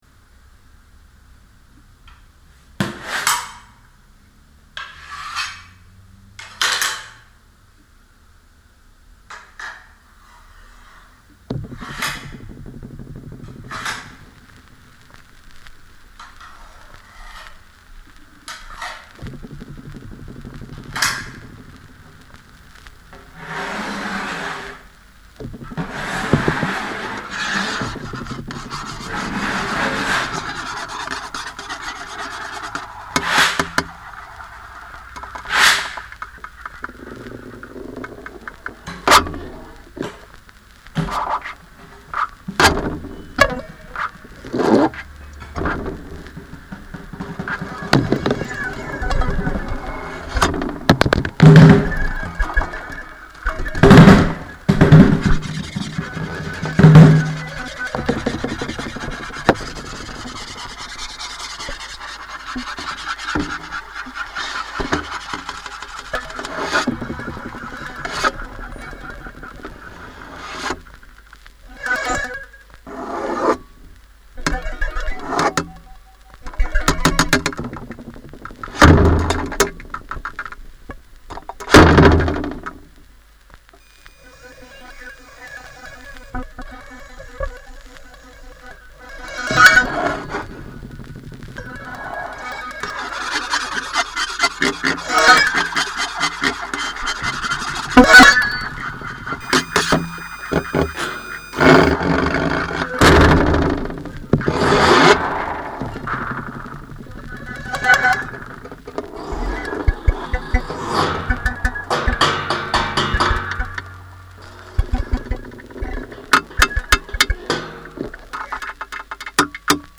.:Orgon-Schallwellen:. ist ein Mannheimer Projekt für experimentelle und konkrete Musik